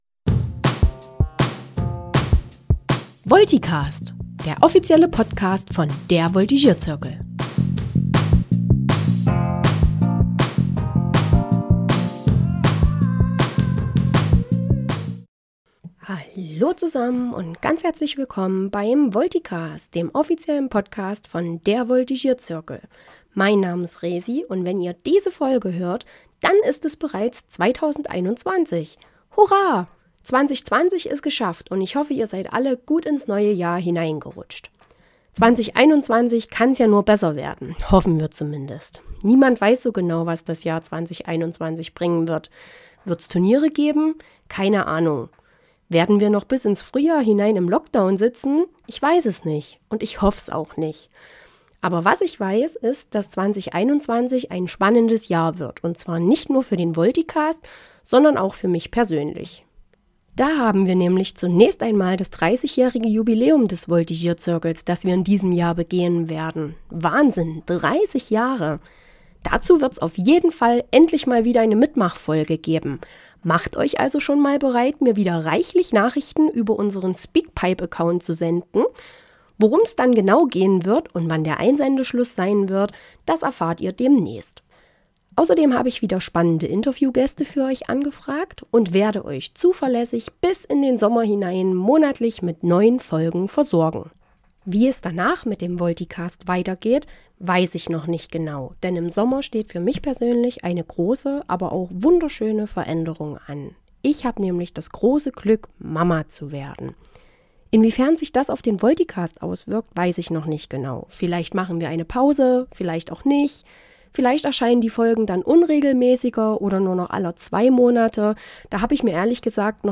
Musik Intro/Outro